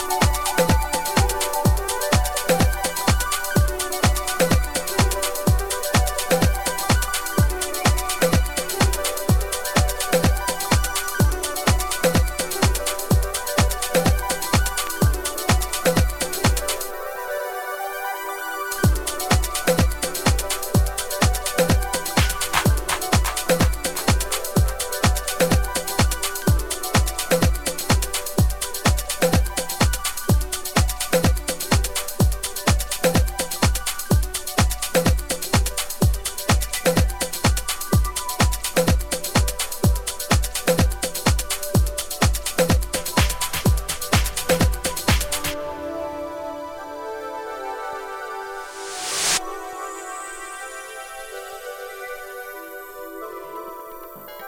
正に極上のDeep House。